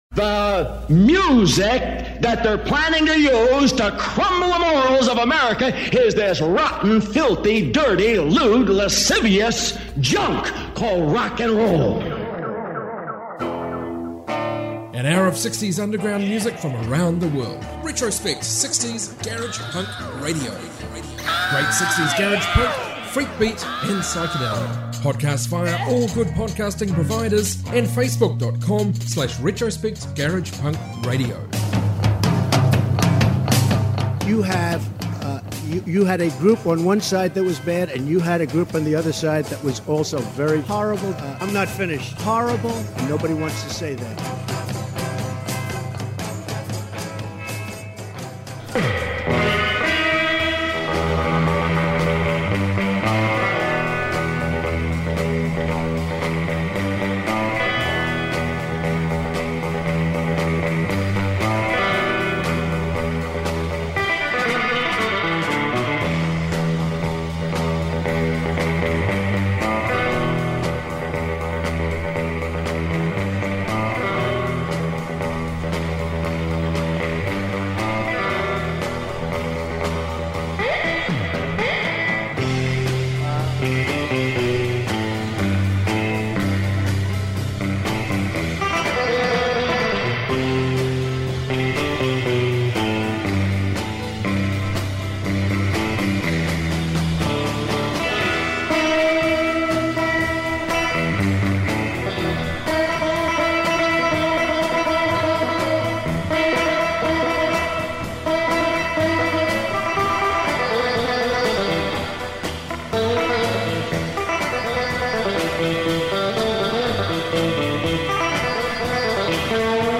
60s global garage rock